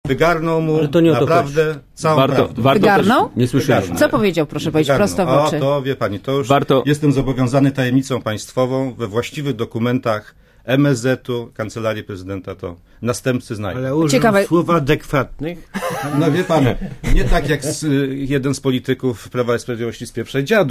Zaproszeni do studia Radia ZET politycy zgodnie krytykowali Aleksandra Kwaśniewskiego za zbyt długie milczenie w sprawie pobicia Polaków w Moskwie.
Fragment programu - Siódmy Dzień Tygodnia